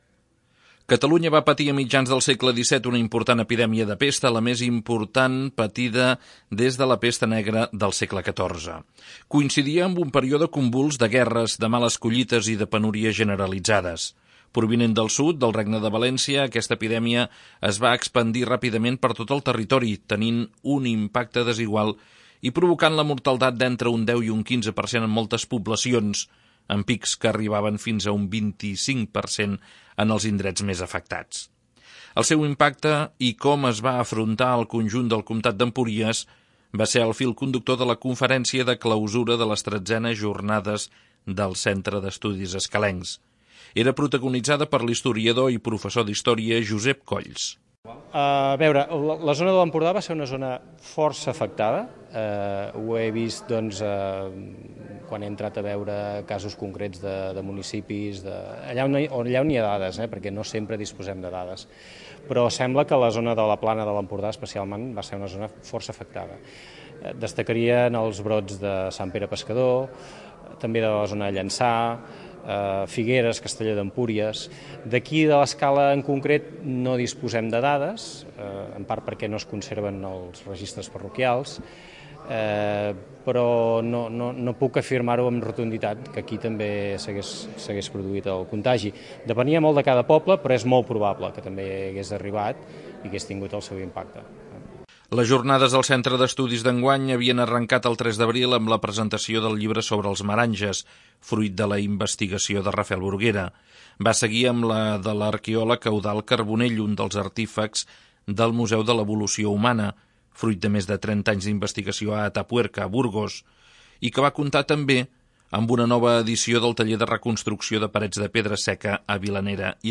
2. L'Informatiu